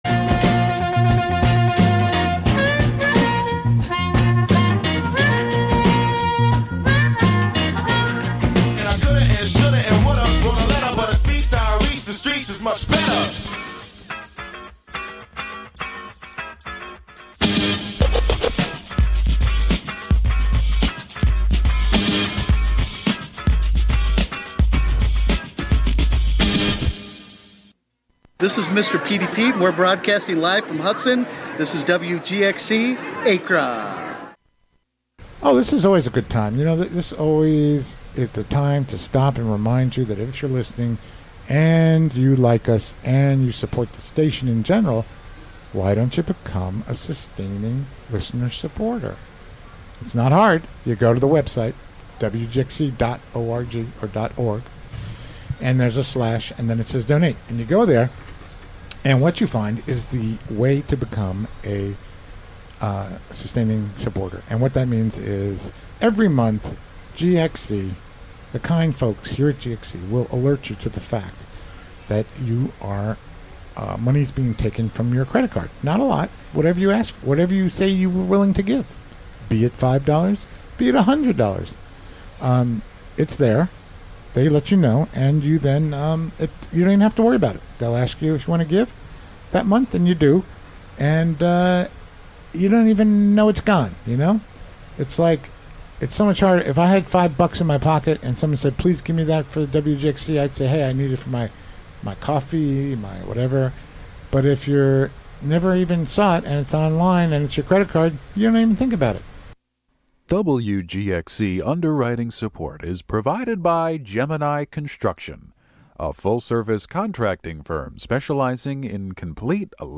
7pm Monthly program featuring music and interviews.
Broadcast live from the Hudson studio.